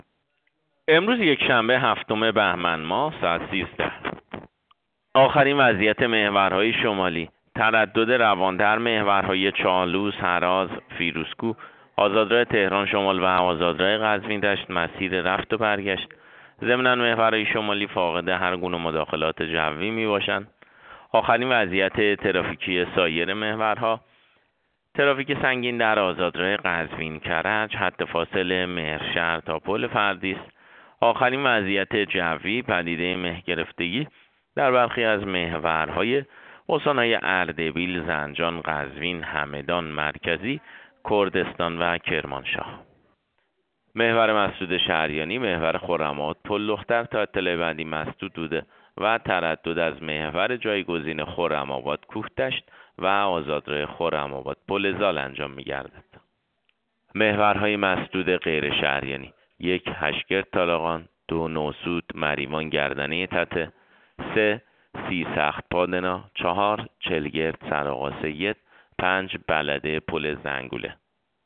گزارش رادیو اینترنتی از آخرین وضعیت ترافیکی جاده‌ها ساعت ۱۳ هفتم بهمن؛